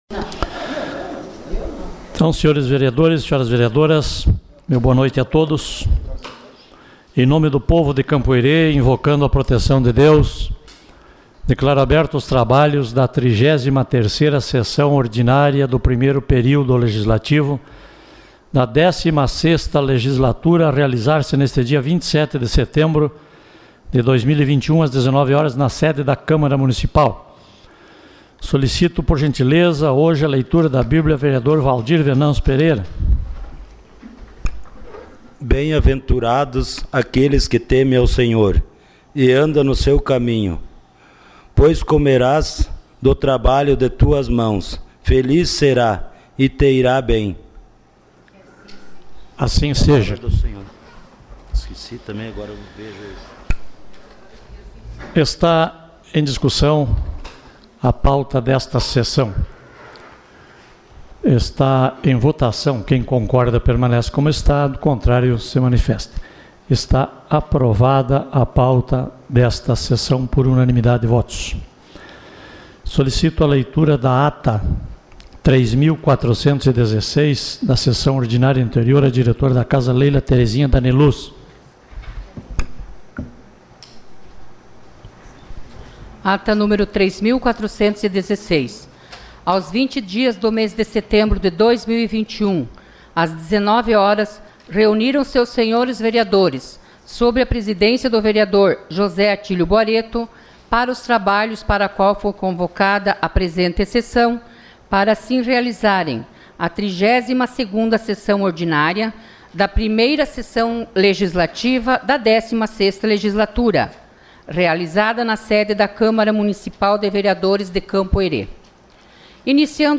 Sessão Ordinária dia 27 de setembro de 2021